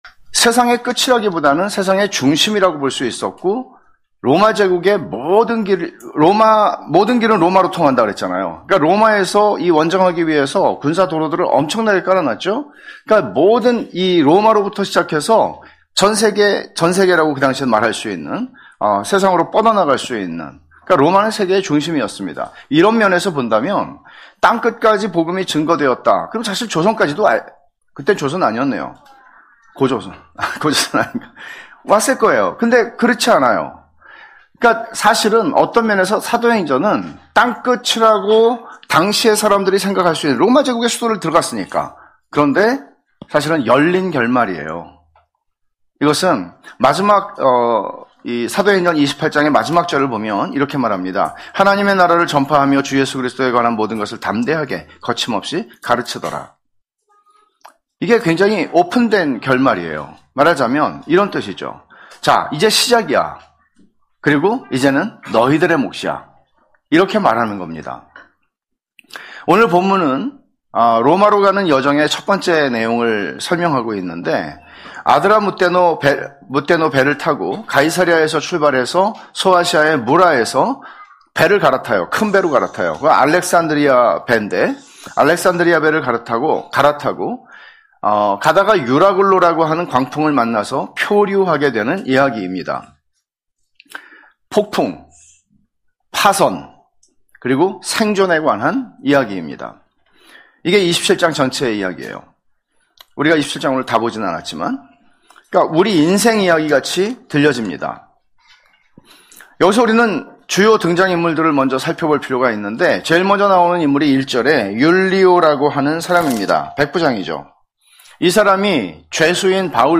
[사도행전 강해](46) 로마로 가는 길-"나는 하나님을 믿노라" (행 27:1-26)